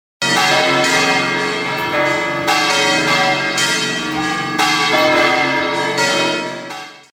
Im Glockenhaus angekommen, bestaunten wir die 24 Glocken, die in die verschiedenen Himmelsrichtungen ausgerichtet sind.
Einige der Glocken werden um die Achse ihrer hohen Holzjoche gedreht, manche auch nur bis in die Waagerechte gependelt und wieder andere werden mit ihrem Klöppel angeschlagen. Ein ohrenbetäubender Lärm, wenn man direkt daneben steht.